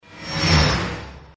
OvertakingSound_3.mp3